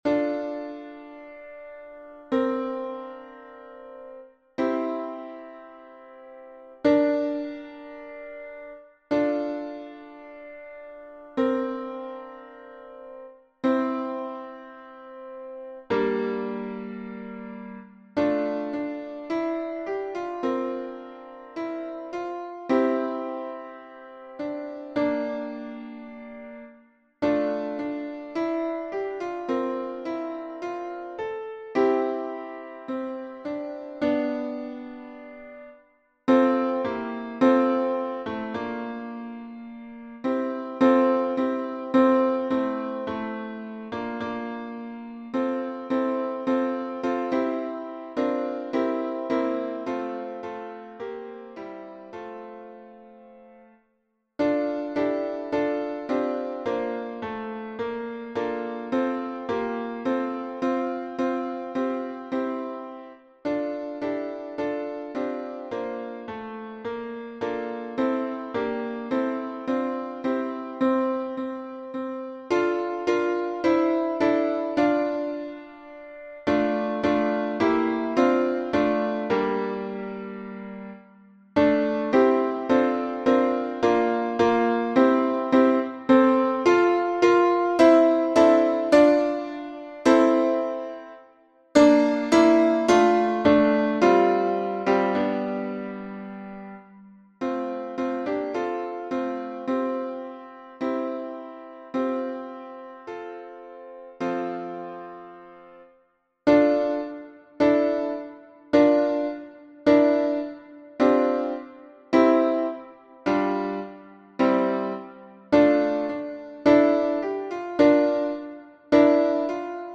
MP3 version piano
Toutes les voix